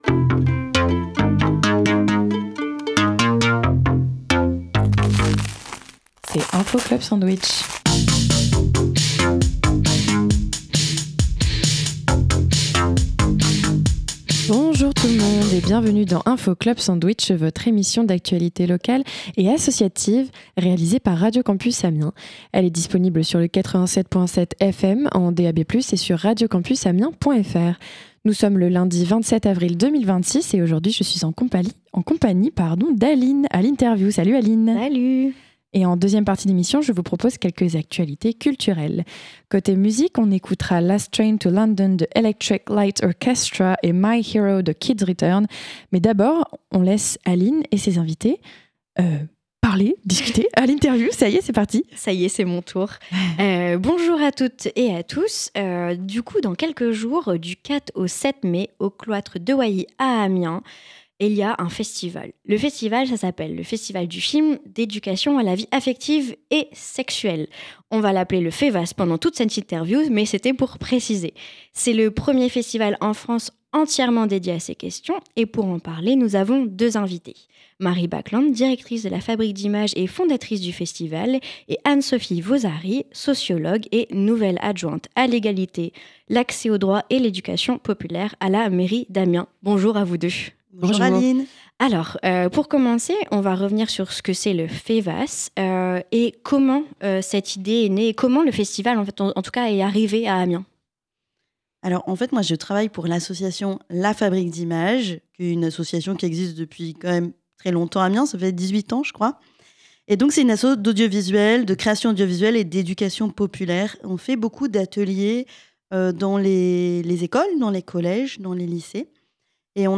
Attention, durant l’interview il est fait mention de thèmes sensibles comme l’inceste et les violences sexistes et secuelles.